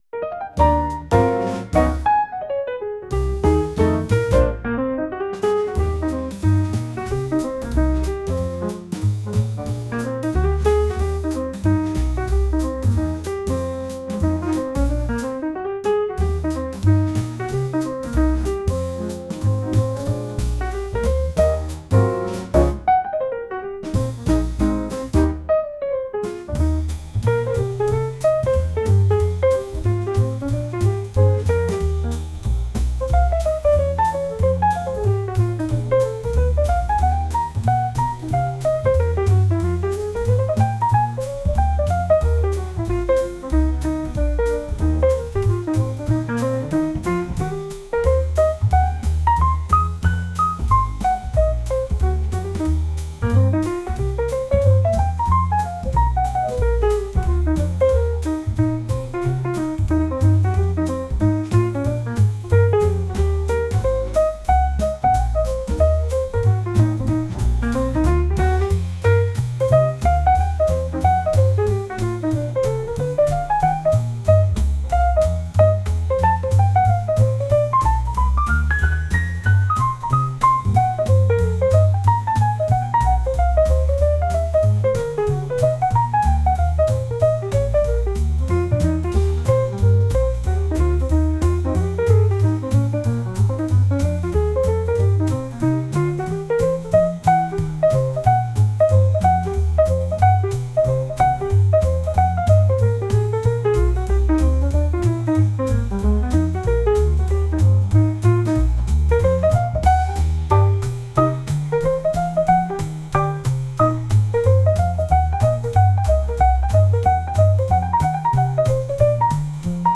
ジャンルJAZZ
楽曲イメージワクワク, 幸せ, 日常, 爽やか
シーン店舗BGM